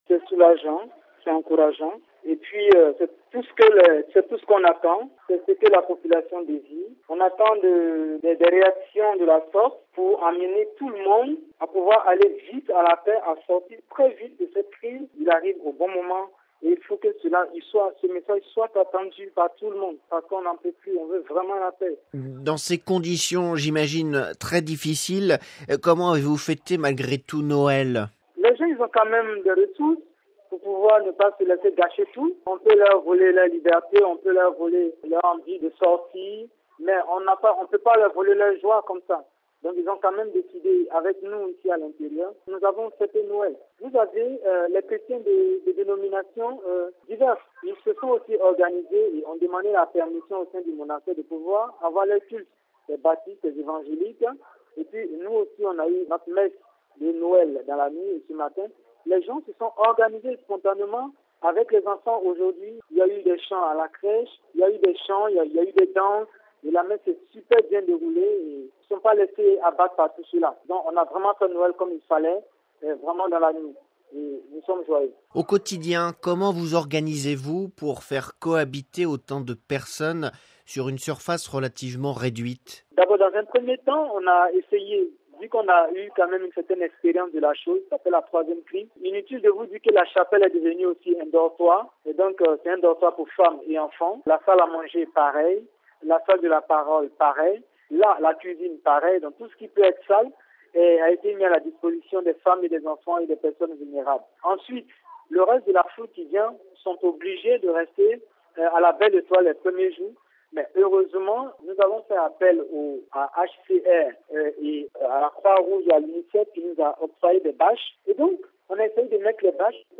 (RV) Entretien - « La République Centrafricaine, souvent oubliée des hommes » : le Pape François, lui, n’a pas oublié de penser à ce pays plongé dans la plus grande confusion depuis plusieurs semaines dans son message de Noël.